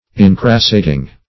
Incrassating - definition of Incrassating - synonyms, pronunciation, spelling from Free Dictionary